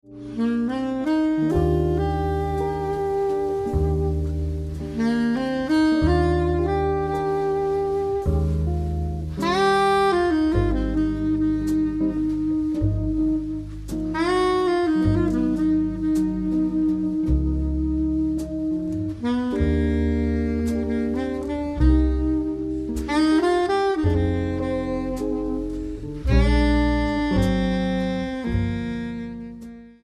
Live at Airegin on DEC. 9, 2003